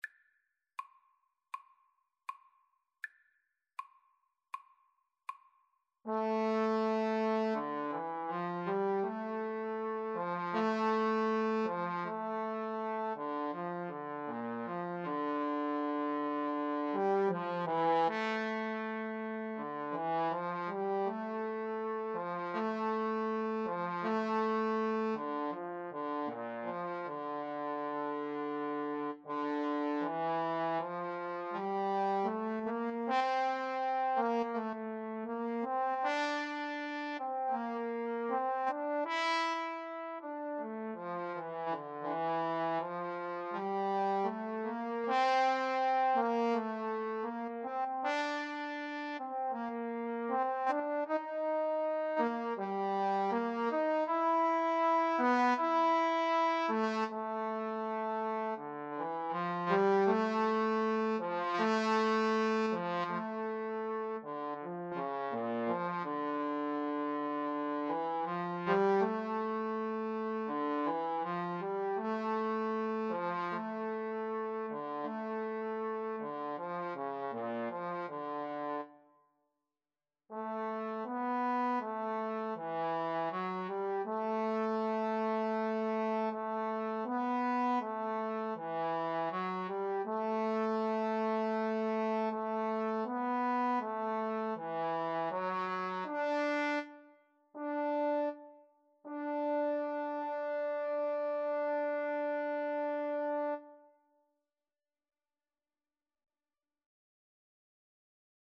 Free Sheet music for Trombone Duet
D minor (Sounding Pitch) (View more D minor Music for Trombone Duet )
Andante = 80
Classical (View more Classical Trombone Duet Music)